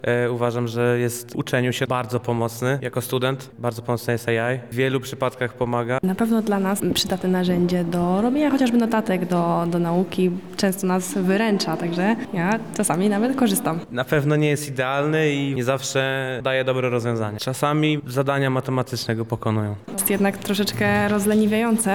O potencjalnych zaletach i wadach sztucznej inteligencji mówili nam uczestnicy szkolenia